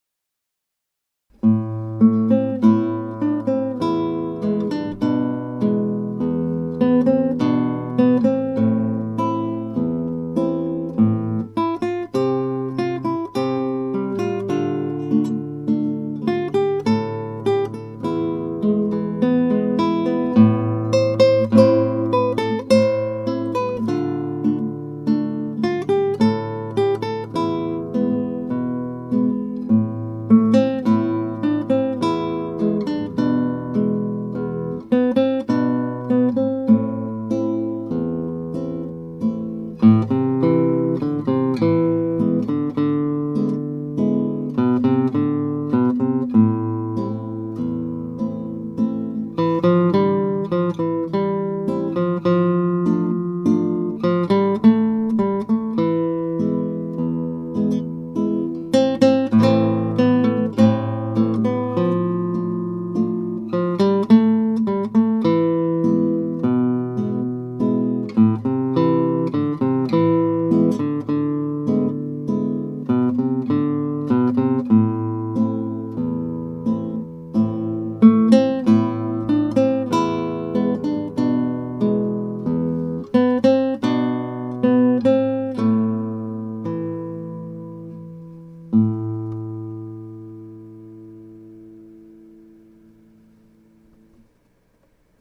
Guitar amatuer play